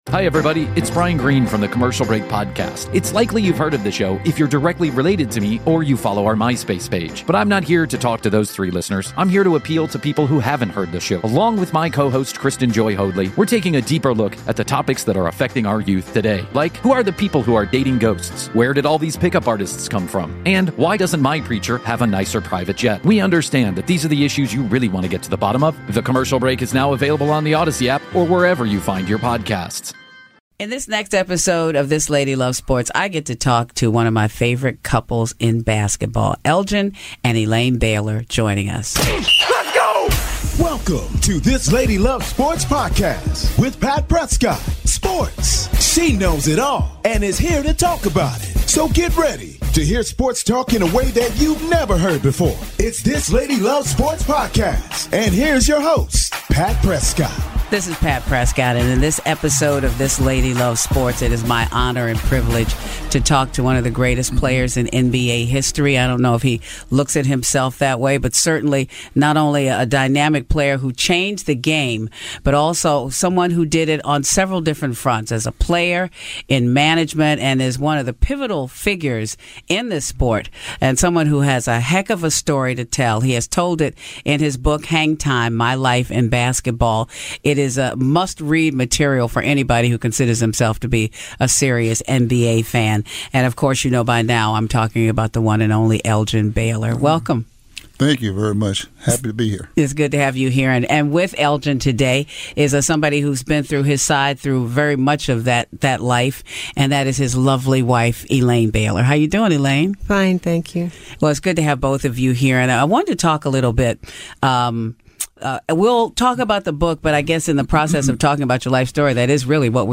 They stopped by for a revealing chat about how they met, about Elgin’s brilliant career and the racism he faced in the early days of the NBA.